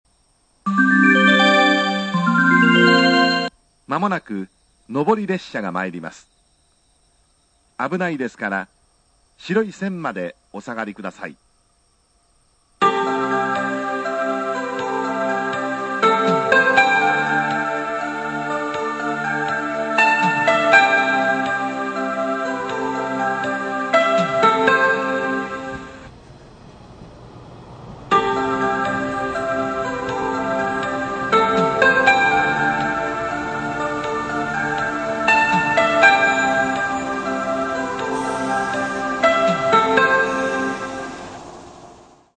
スピーカー：TOA小
冒頭メロディー＋放送（男性）＋接近メロディー TB接近A2+広島4 PCM
高音・低音がはっきり出ず、乾いた音質とともに、音割れが発生します。